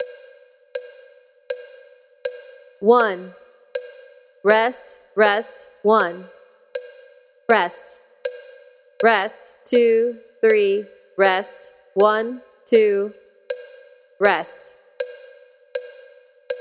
For these exercises you will need a metronome to click the beat and one beat equals one quarter note.
Another method to deal with rests involves saying “REST” out loud for the passing time. Each “REST” equals one beat.
Counting rests, Example 2